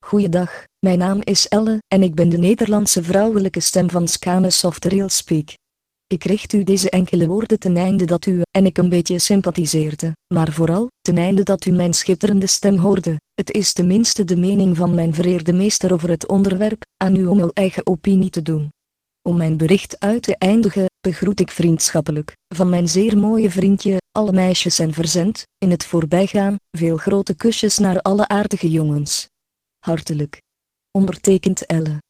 Texte de démonstration lu par Ellen (Nuance RealSpeak; distribué sur le site de Nextup Technology; femme; néerlandais